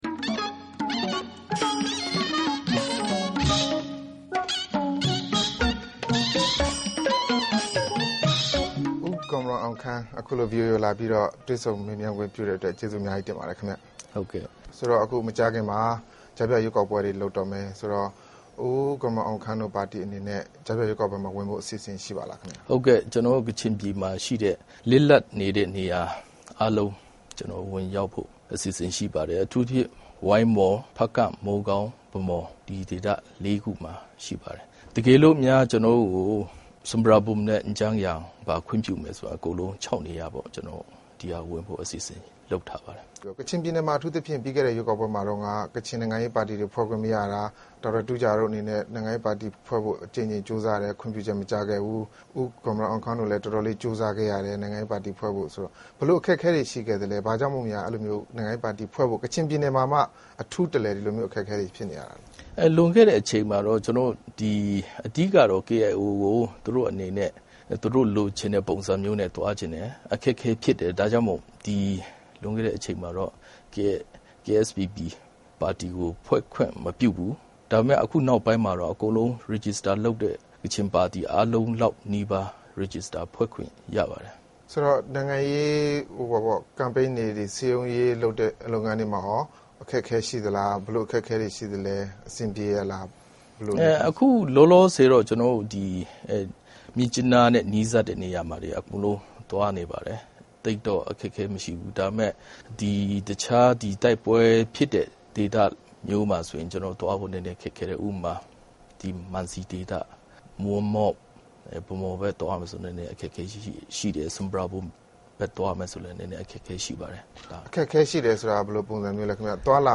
တွေ့ဆုံမေးမြန်းခန်း